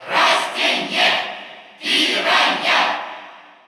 Category: Crowd cheers (SSBU) You cannot overwrite this file.
Piranha_Plant_Cheer_Russian_SSBU.ogg